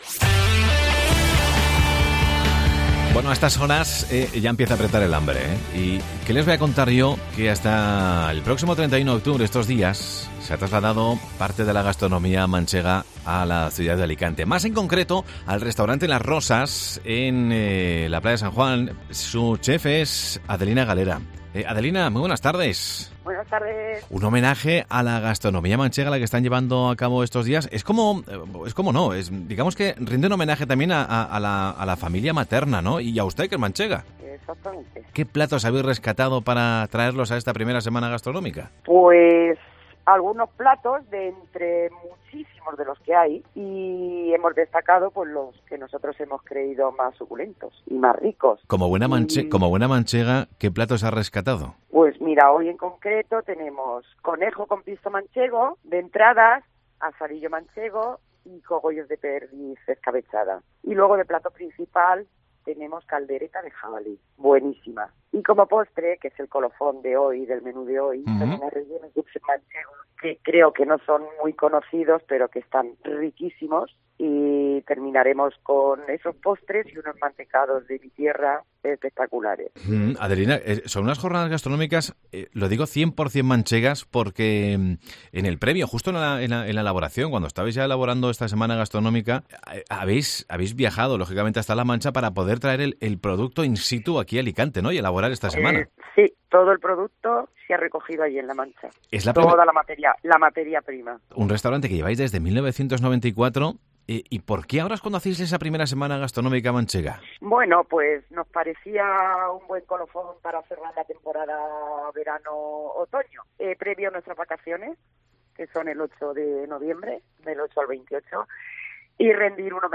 Del 26 al 31 de octubre el restaurante Las Rosas celebra la I Semana gastronómica manchega. Escucha la entrevista